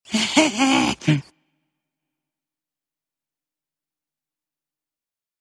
conky - mischief laugh